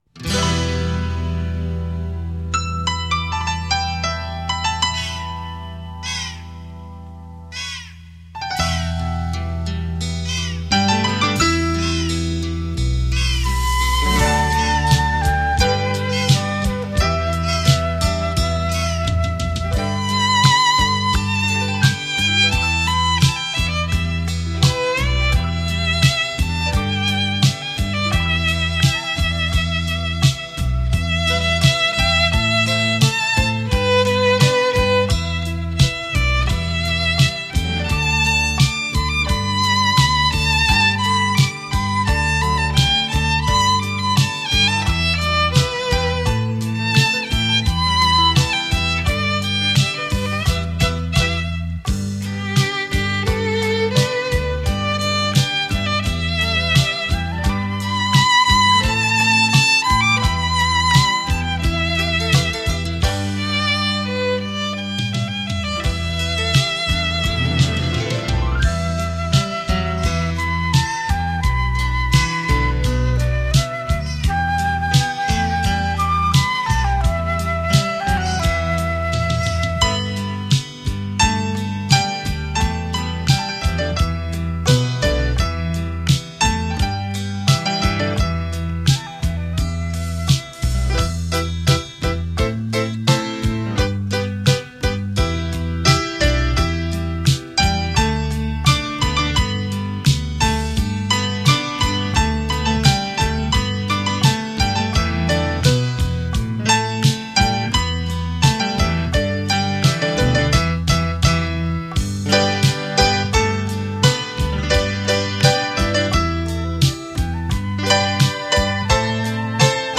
唱片风格：纯音乐